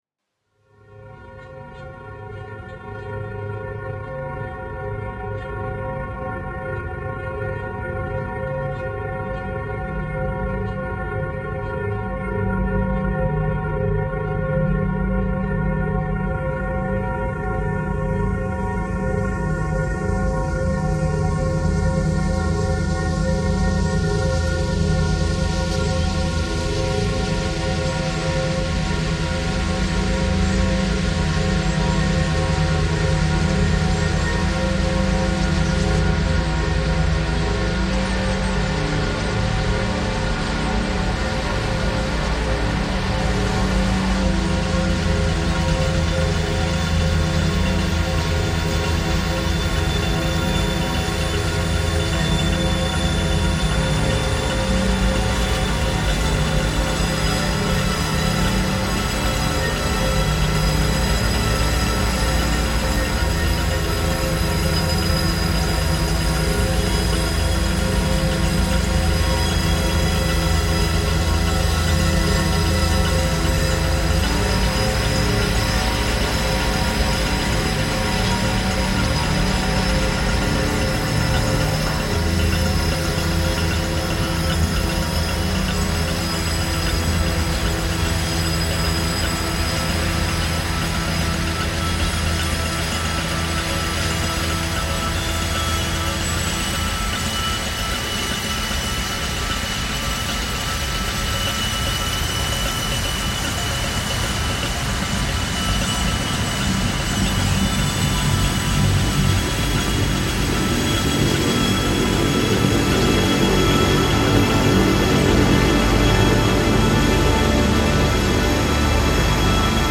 Otmoor starlings reimagined